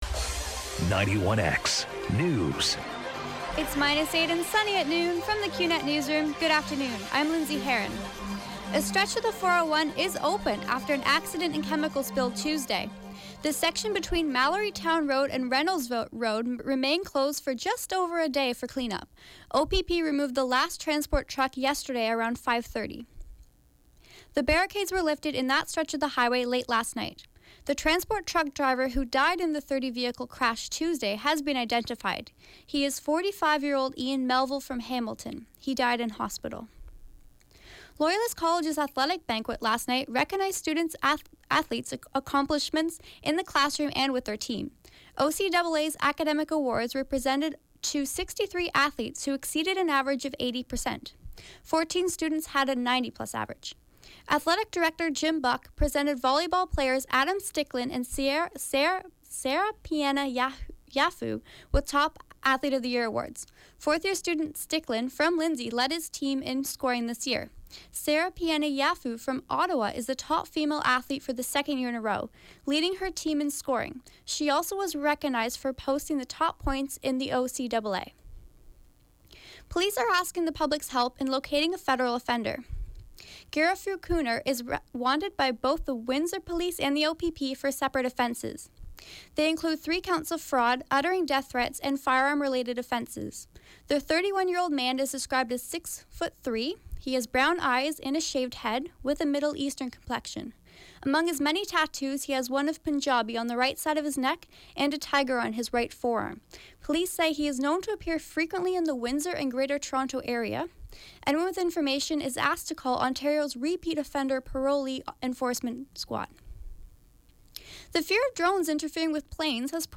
91X FM Newscast — Thursday, March 16, 2017, noon